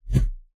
kick_short_whoosh_23.wav